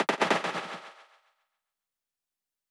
game_over_sand.wav